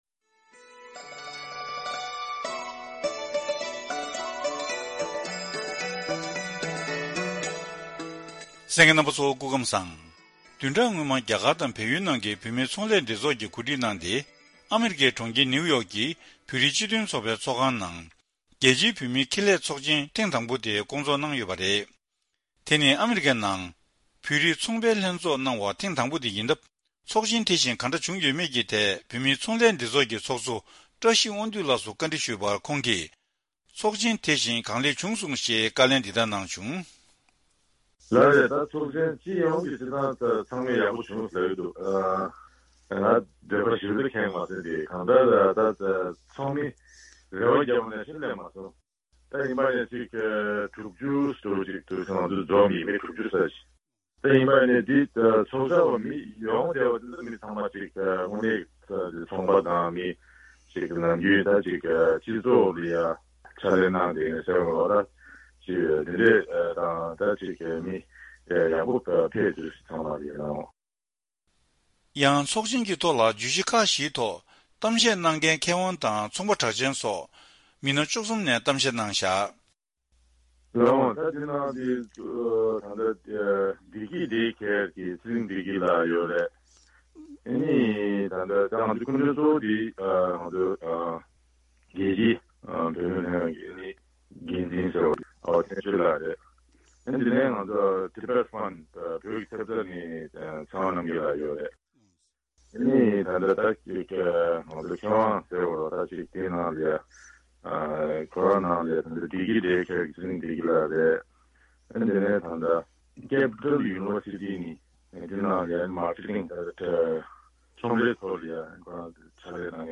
བཀའ་འདྲི་ཞུས་ནས་ཕྱོགས་སྒྲིགས་ཞུས་པ་ ཞིག་གསན་རོགས་གནང་།